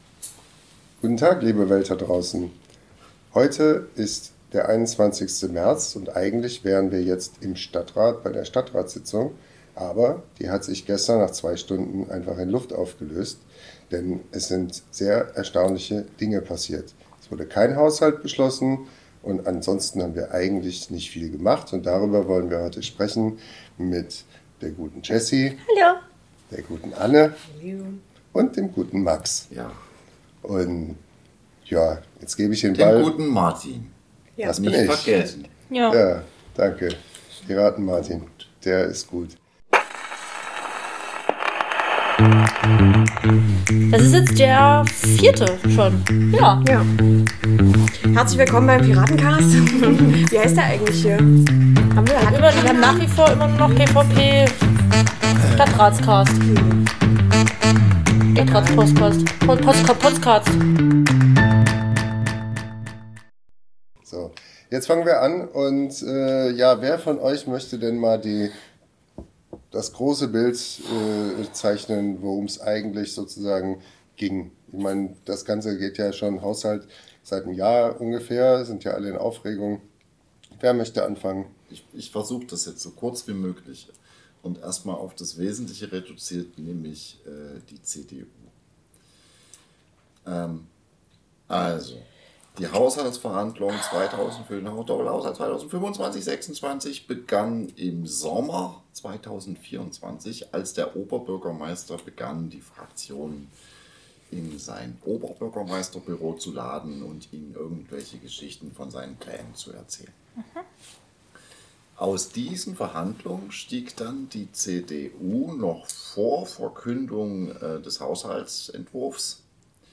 Beschreibung vor 1 Jahr Jessica Roitzsch, Martin Schulte-Wissermann, Anne Herpertz und Max Aschenbach im Gespräch über die Stadtratssitzung vom 20.03.2025. Findet heraus, wie die CDU nichtmal das bisschen Nazihaushalt alleine schafft, wie verkehrspolitische Präferenzen die DVB killen und wie lange es dauert, ein Fahrradparkhaus zu bauen.